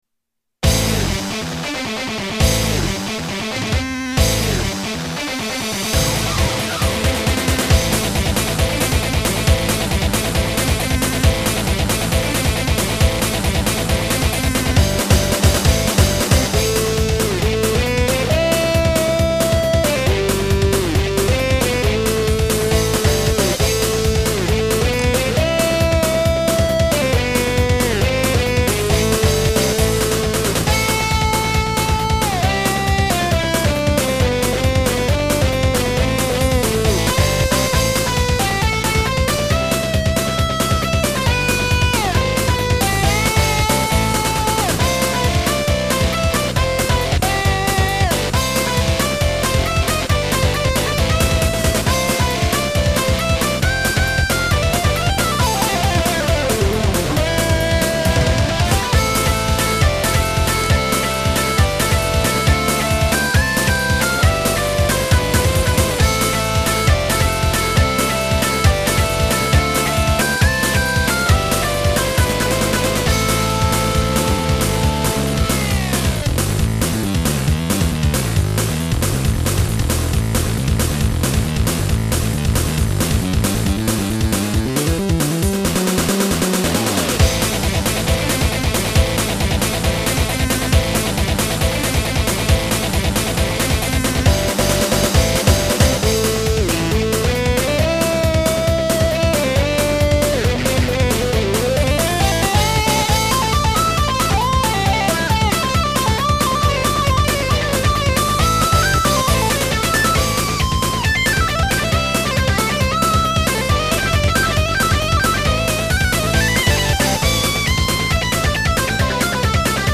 疾走するギターと、僅かに見え隠れする和風の味付けが上手くキャラを 表していると思います。